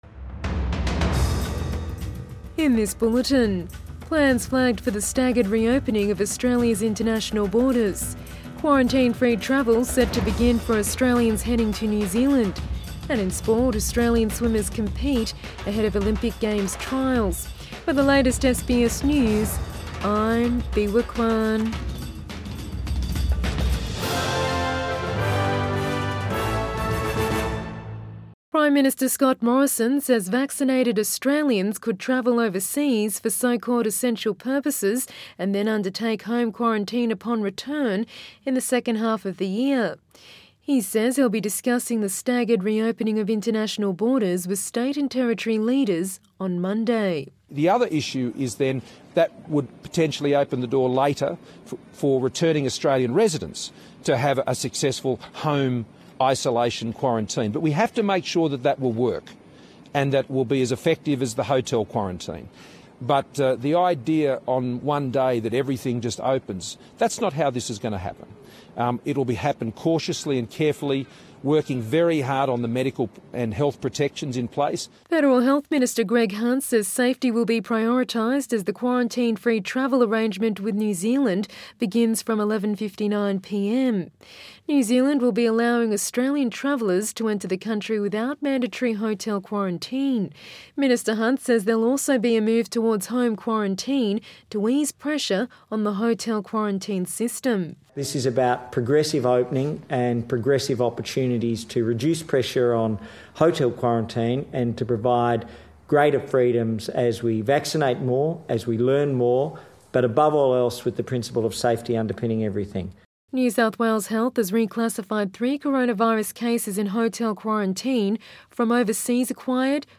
PM Bulletin 18 April 2021